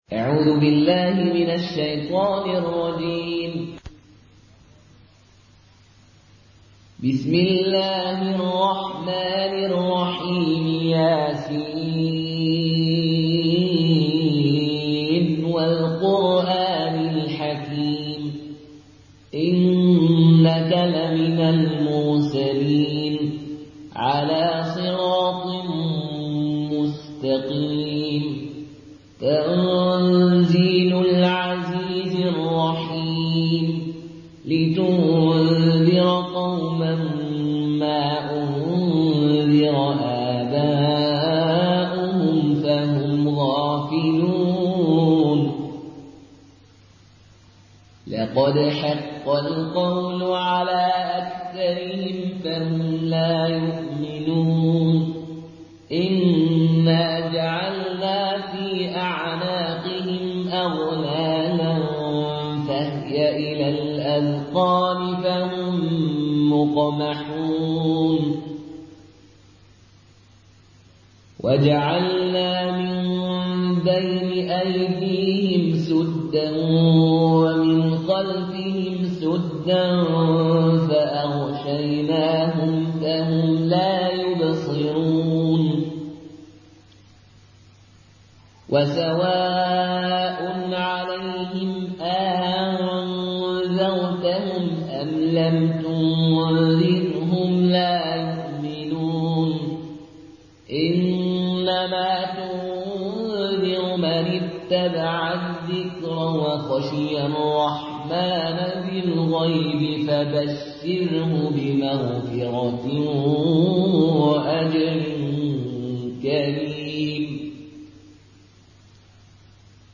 Une récitation touchante et belle des versets coraniques par la narration Qaloon An Nafi.